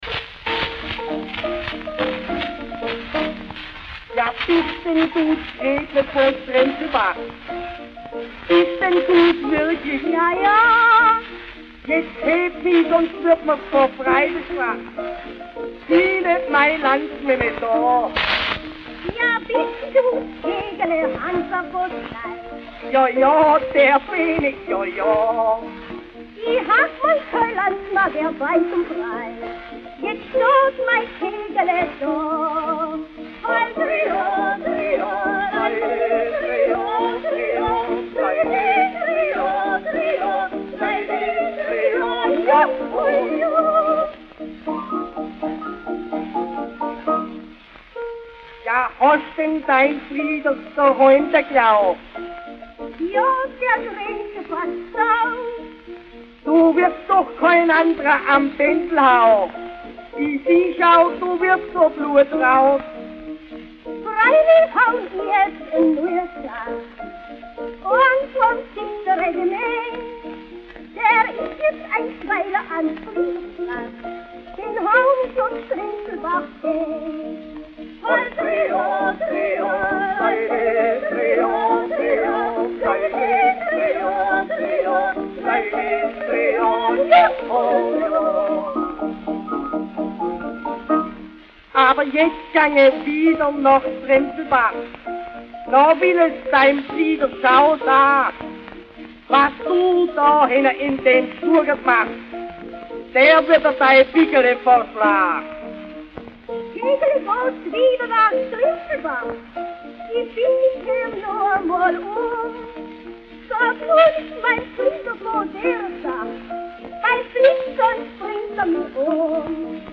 Quelle: Schellackplattensammlung